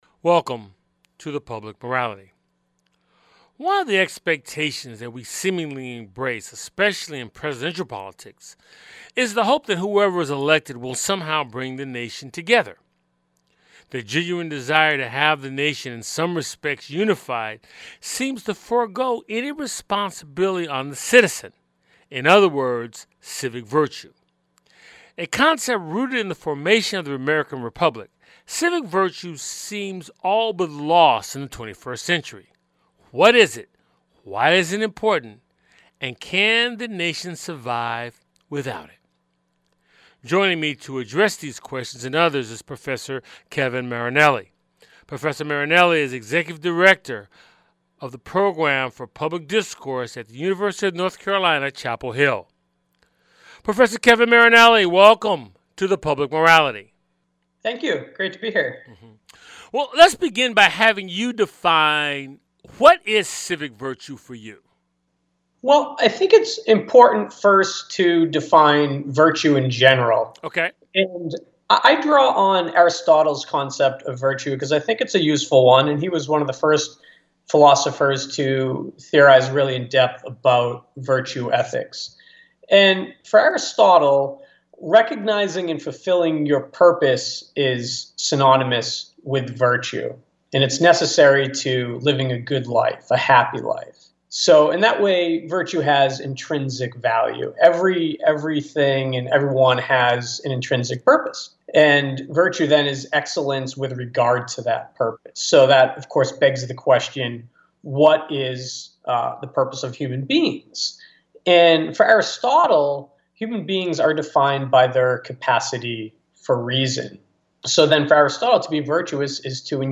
It's a weekly conversation with guest scholars, artists, activists, scientists, philosophers and newsmakers who focus on the Declaration of Independence, the Constitution and the Emancipation Proclamation as its backdrop for dialogue on issues important to our lives.